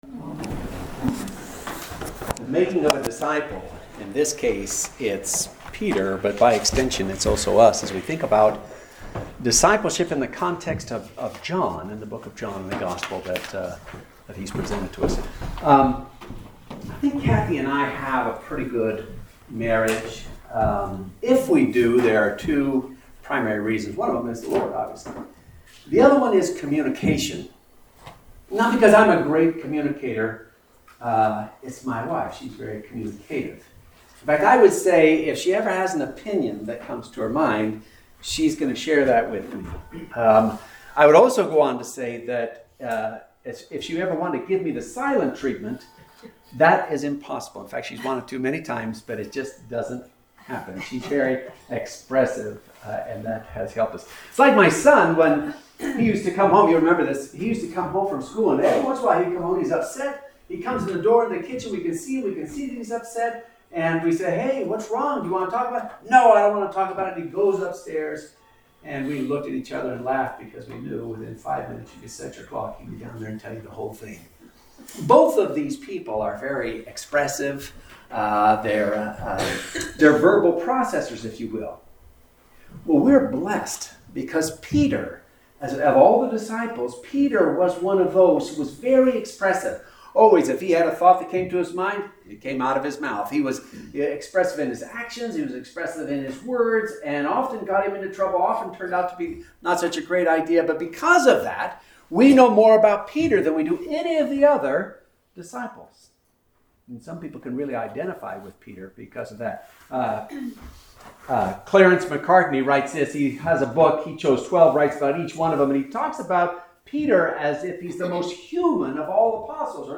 Sermons | Sand Lake Chapel